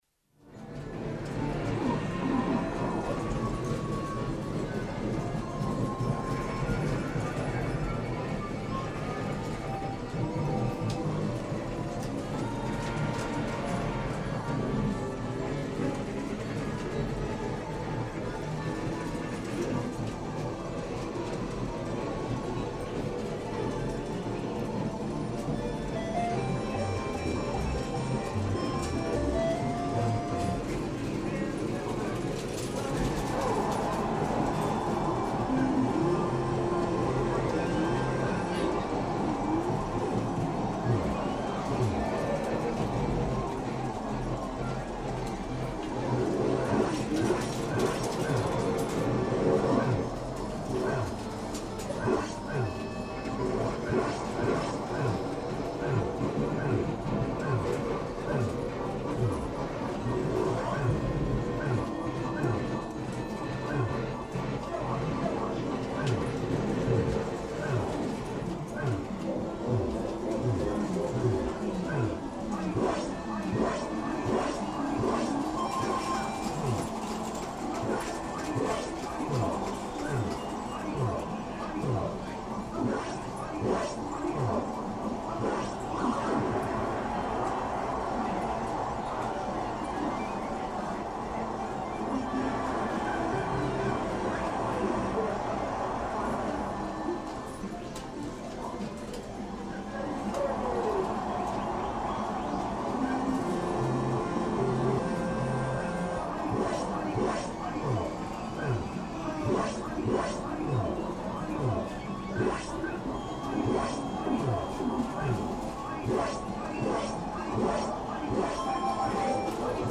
Nearly 7 minutes of Wizard of Wor, Q*bert, Pole Position, Popeye, Gorf, Centipede, and many more classic games in their prime.
For those who collect ambient arcade background noise, more examples can be found
arcadenoize.mp3